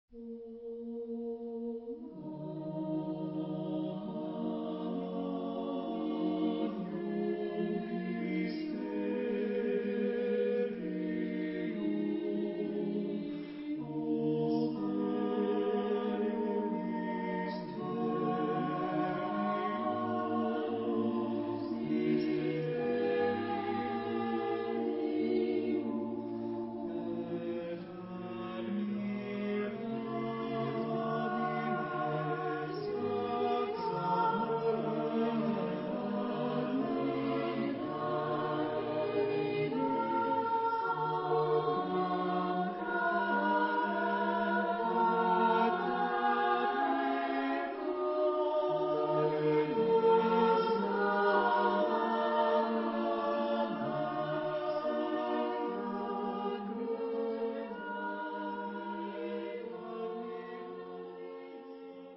Genre-Stil-Form: geistlich ; Motette
Chorgattung: SATB  (4 gemischter Chor Stimmen )
Tonart(en): G-Dur ; e-moll